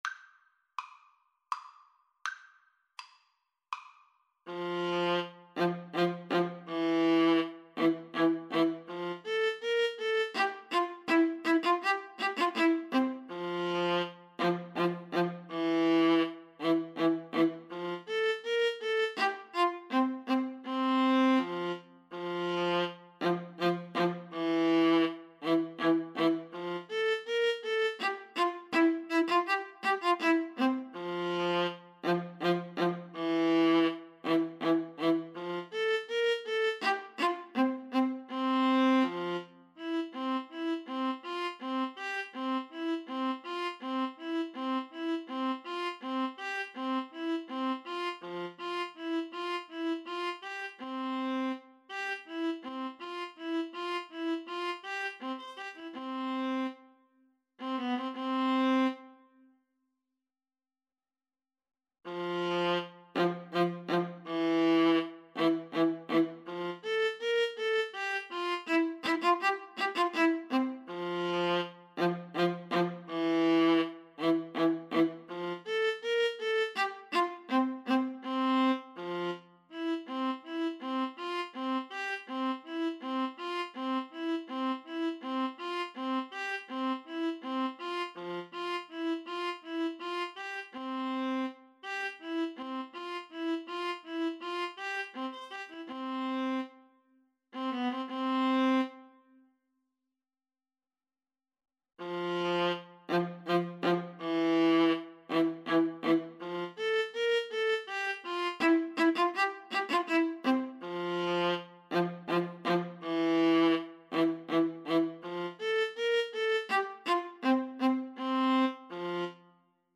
• Unlimited playalong tracks